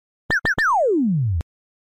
Genre: Nada notifikasi